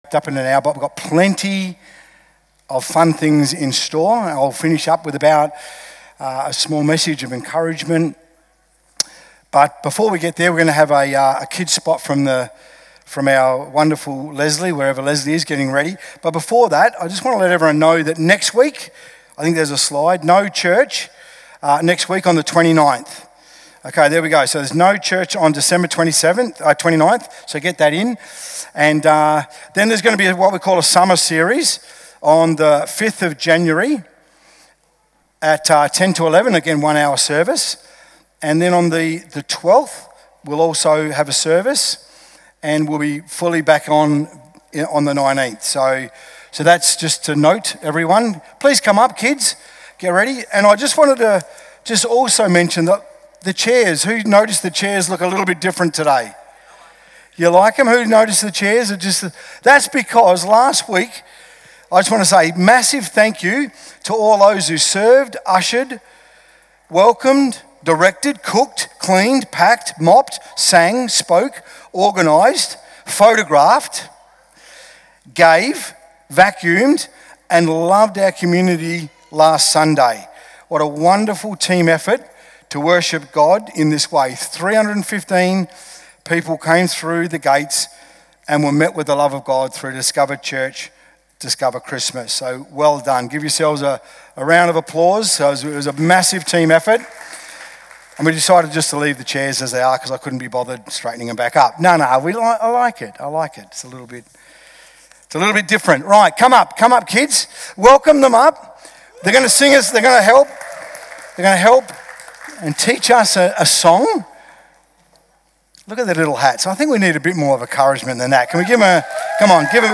Carol Service